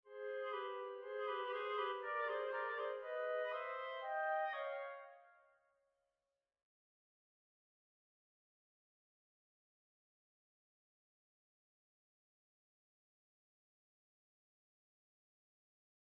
Here is audio for the huge difference in legato handling I mentioned above.
BBCSO really seems to not articulate the first note of each slur.
Noticeable to me are the smooth transitions between sections of slurred notes in NP4 vs. extremely shortened last notes in each of the NPPE groupings.